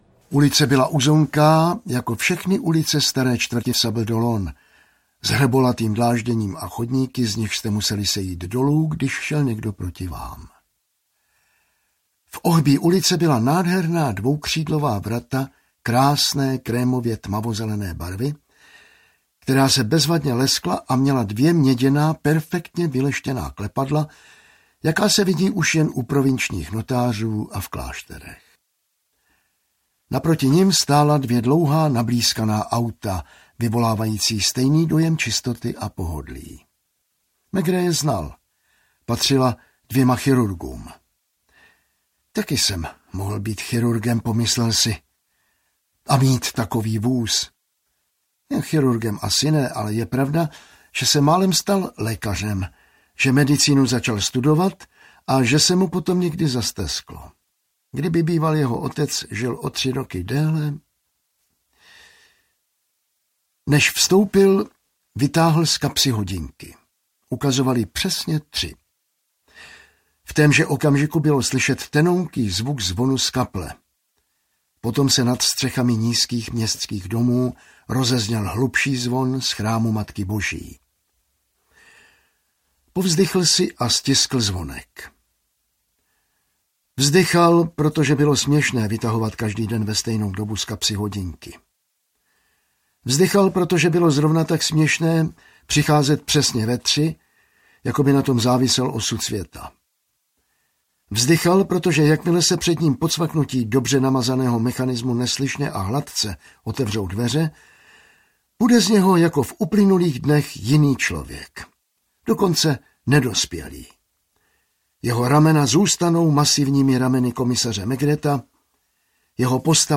Maigret na dovolené audiokniha
Ukázka z knihy